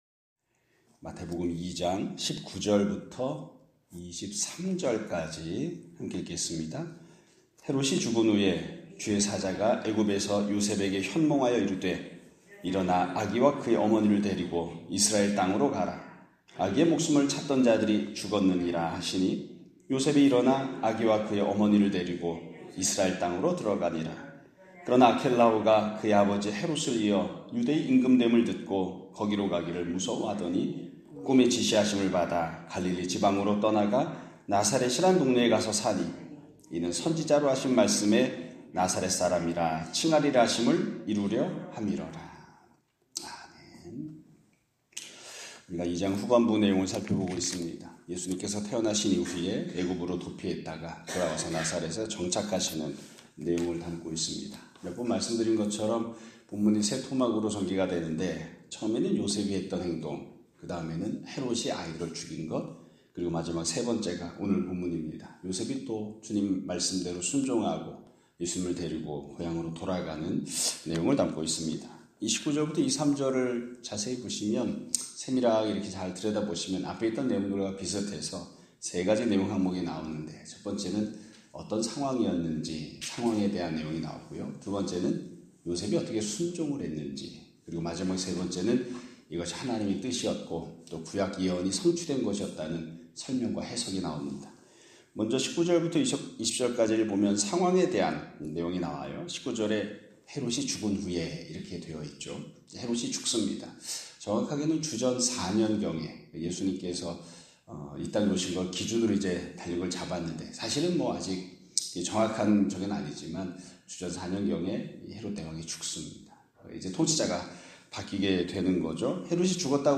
2025년 4월 8일(화 요일) <아침예배> 설교입니다.